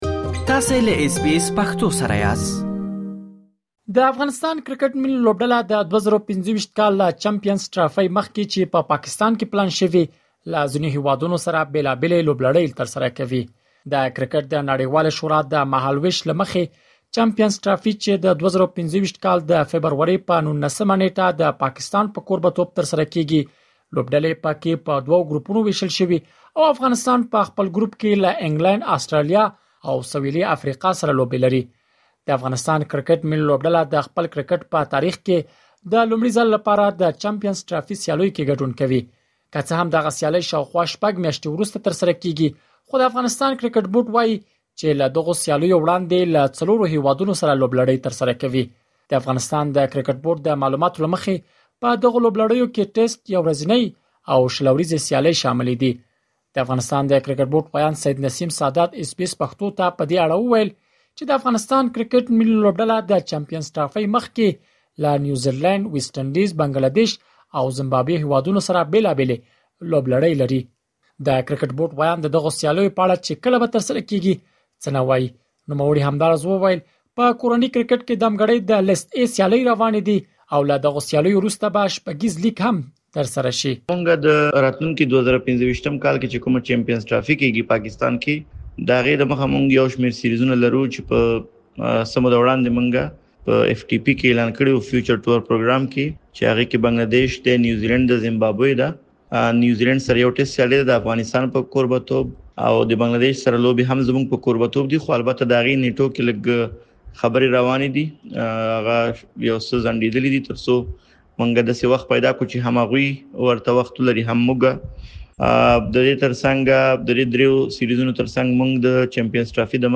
د افغانستان کرېکټ ملي لوبډله د ۲۰۲۵ کال له چمپینز ټرافۍ مخکې چې پاکستان کې پلان شوې له ځینو هېوادونو سره بېلابیلې لوبلړۍ ترسره کوي. لا ډېر معلومات په رپوټ کې اورېدلی شئ.